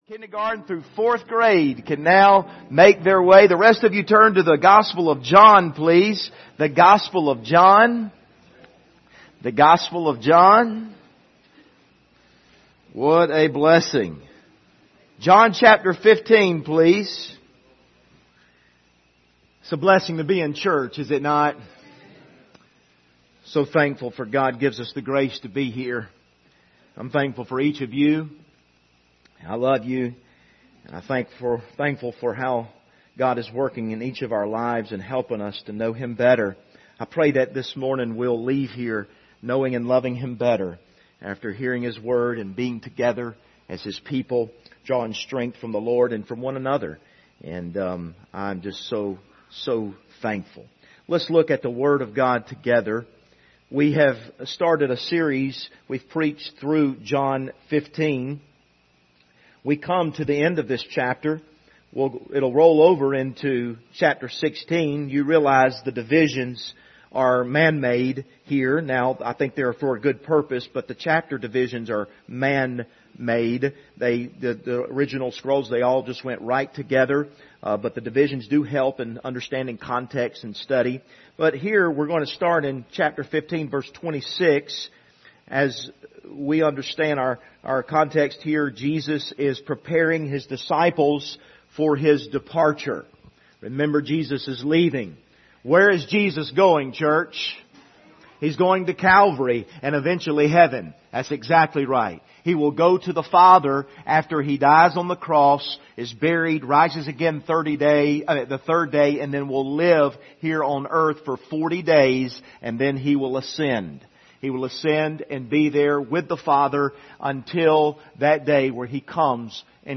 Service Type: Sunday Morning Topics: Holy Spirit